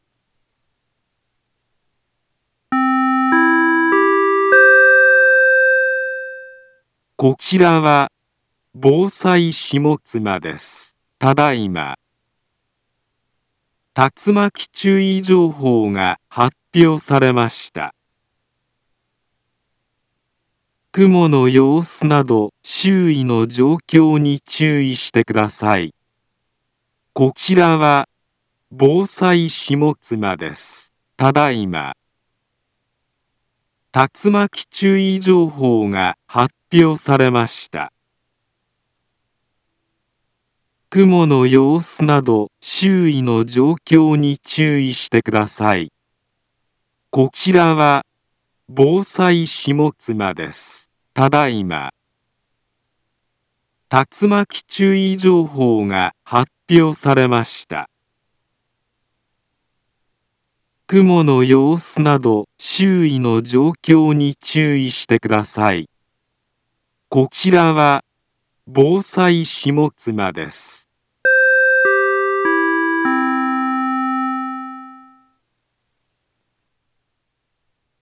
Back Home Ｊアラート情報 音声放送 再生 災害情報 カテゴリ：J-ALERT 登録日時：2024-07-05 20:24:30 インフォメーション：茨城県南部は、竜巻などの激しい突風が発生しやすい気象状況になっています。